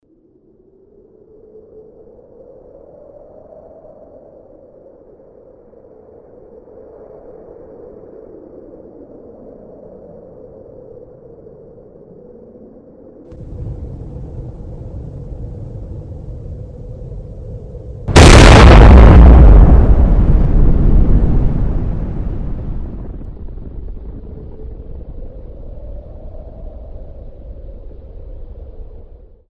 Звуки ядерного взрыва
Мощная ударная волна, нарастающий гул и оглушительный грохот — эти высококачественные аудиофайлы подойдут для создания спецэффектов, монтажа видео или использования в образовательных целях.
Ударная волна